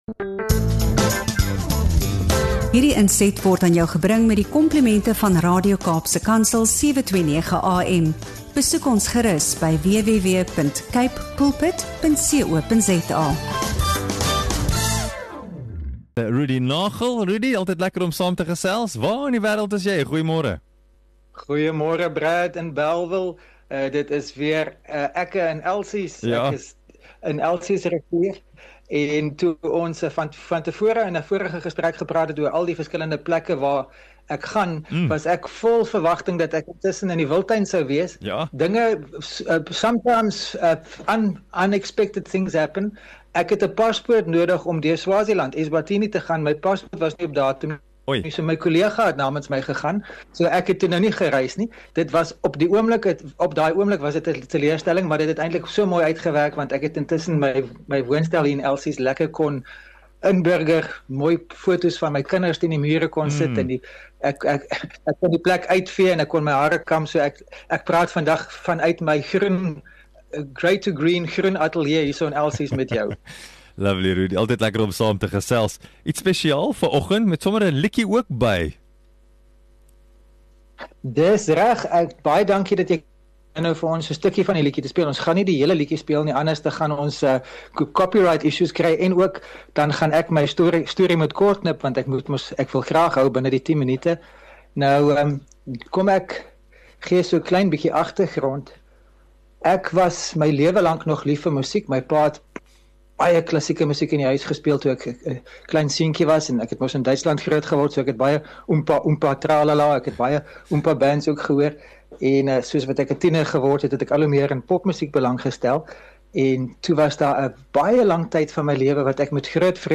onderhoud